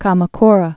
(kämə-krə)